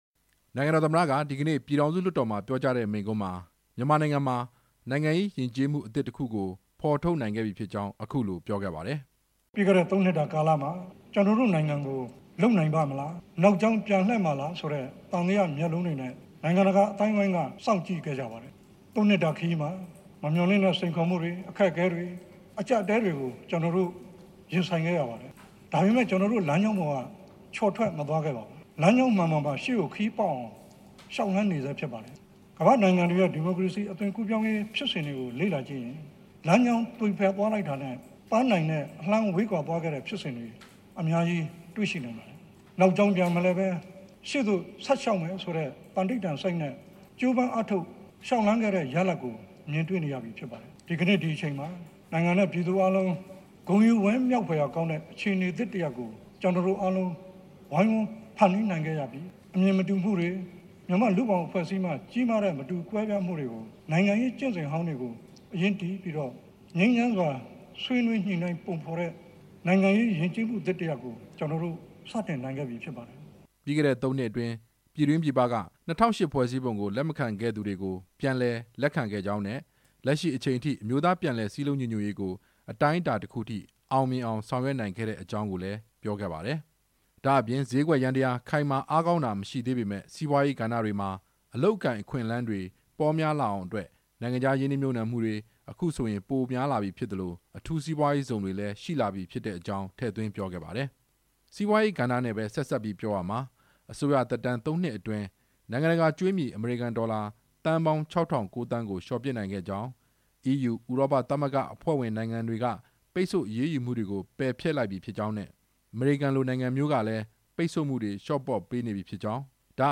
အစိုးရသက်တမ်း ၃ နှစ်ပြည့် အထိမ်းအမှတ်အဖြစ် နိုင်ငံတော်သမ္မတက ပြည်ထောင်စုလွှတ်တော်မှာ ဒီနေ့ မိန့်ခွန်းပြောကြားစဉ် အခုလို ပြောခဲ့တာပါ။